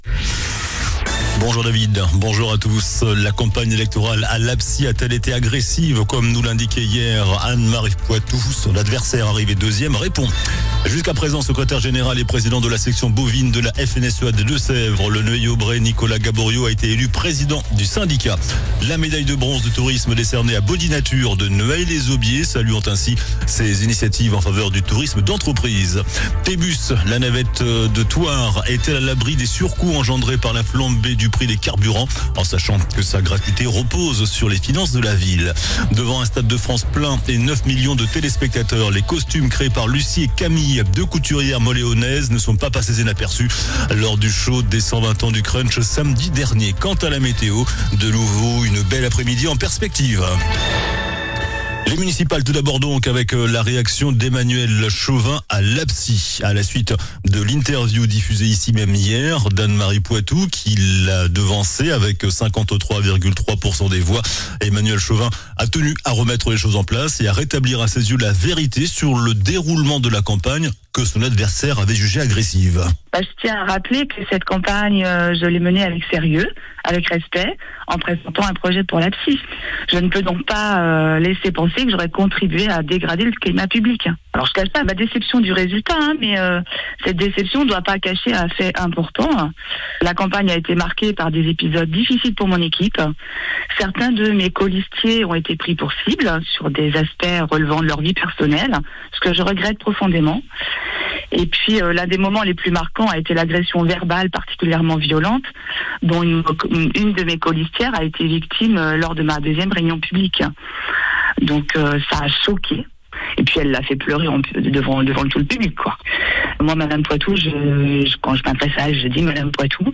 JOURNAL DU JEUDI 19 MARS ( MIDI )